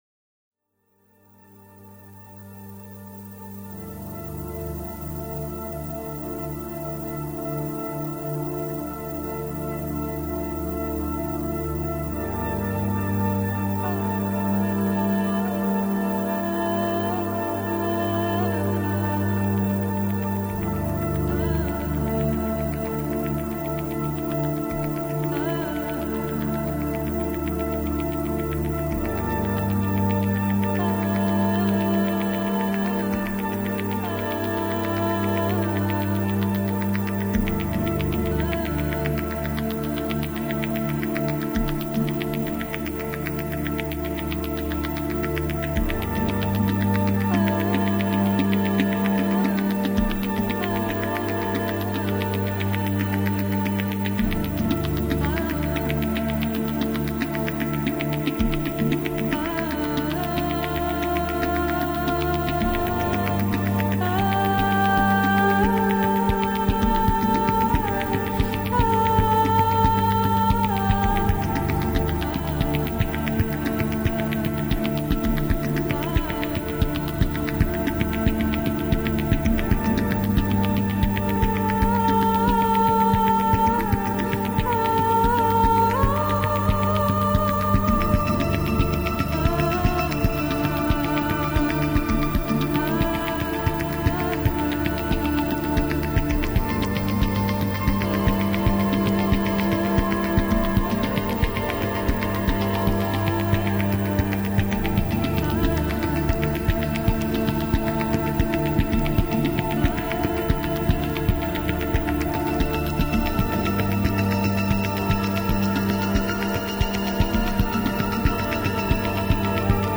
live recordings (ambient):
Recorded during an ambient concert on Feb 16th, 2006.